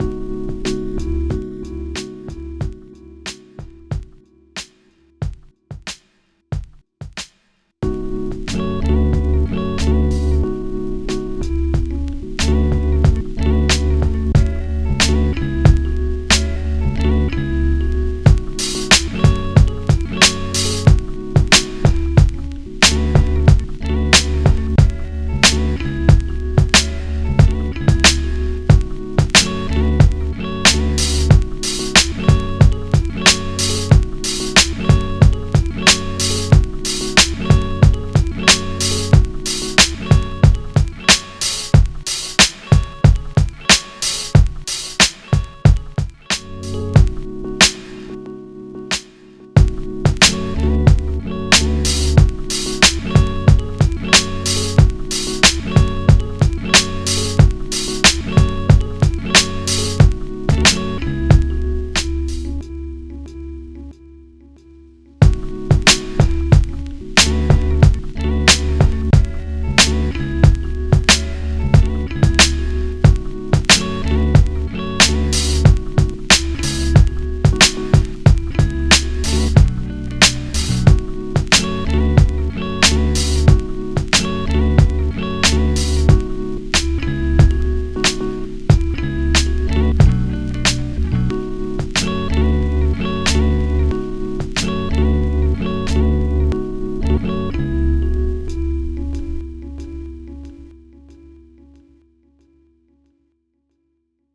french production duo
Soul fi real!